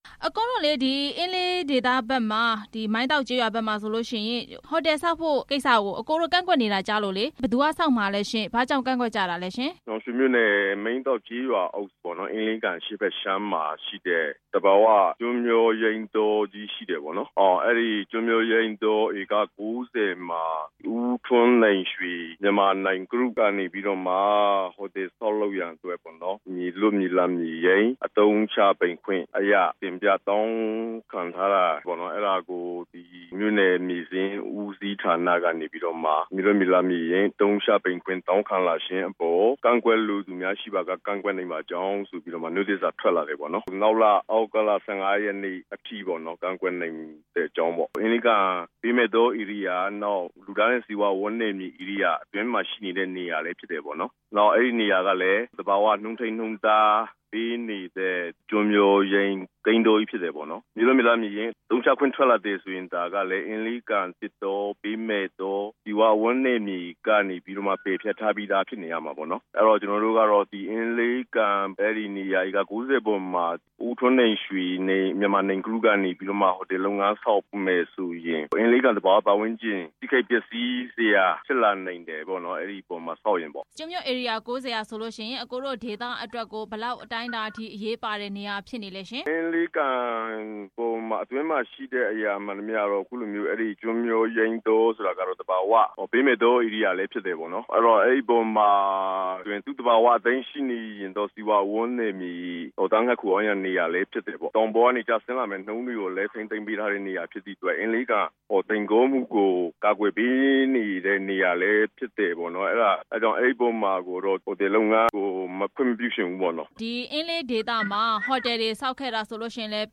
အင်းလေးကန်ထိန်းသိမ်းရေး ဆက်သွယ်မေးမြန်းချက်